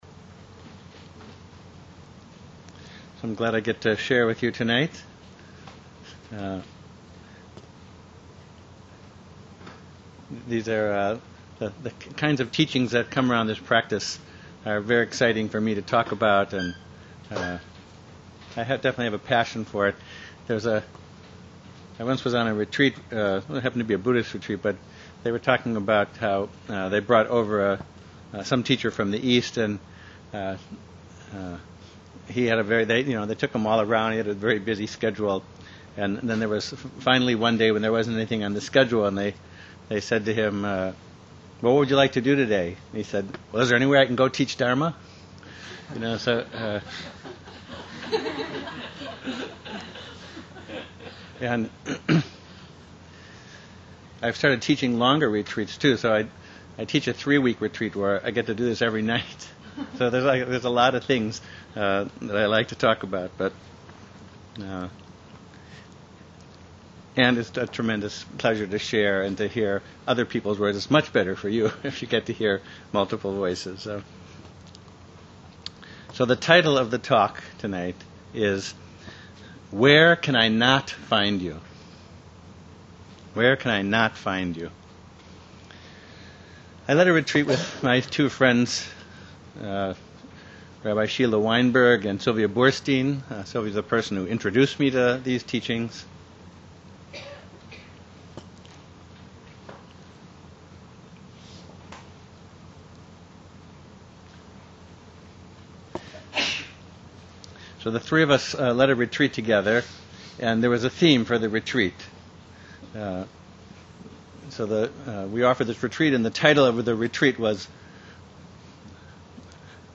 We are pleased to present the fourth and final in a series of lectures given at the Jewish meditation retreat held at Kibbutz Hannaton in the Galil, March 11-17, 2012.